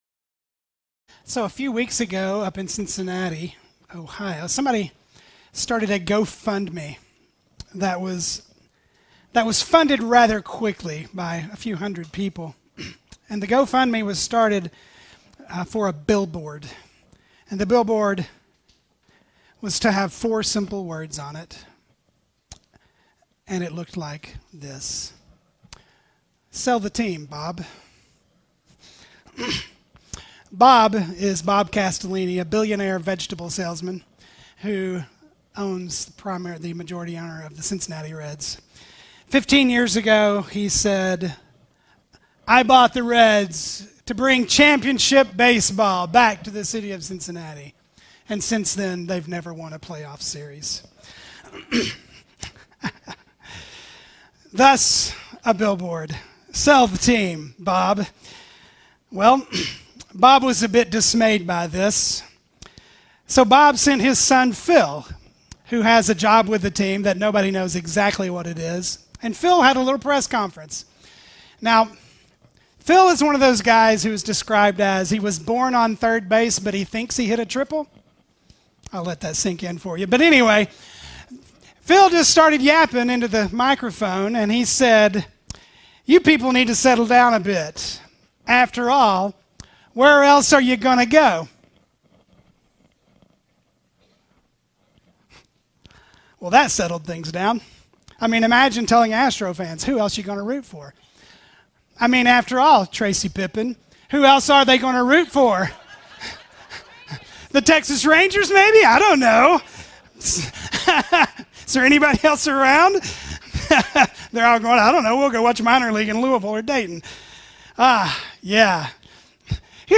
Easter Message 2022